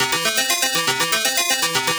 Index of /musicradar/8-bit-bonanza-samples/FM Arp Loops
CS_FMArp C_120-C.wav